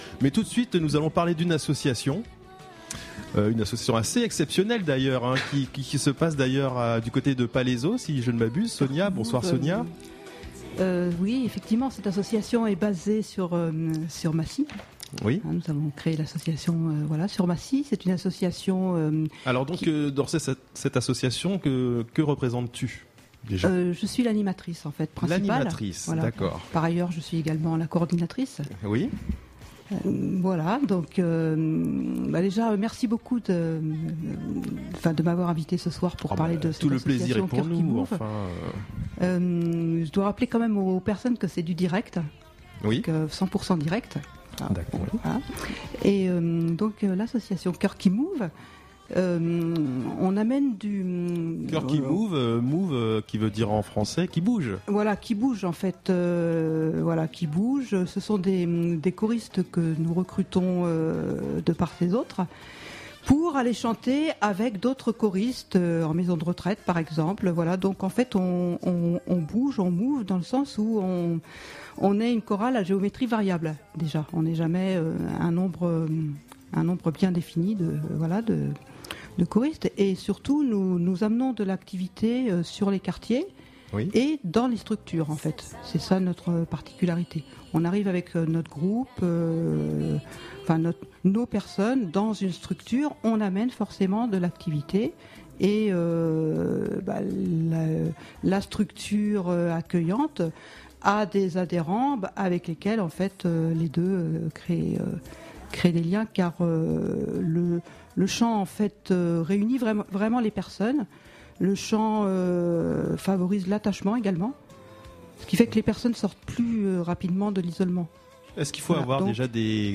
Vous pourrez retrouver ici l’interview, et je vous invite à aller consulter la présentation de la radio dans la rubrique: Nos Amis.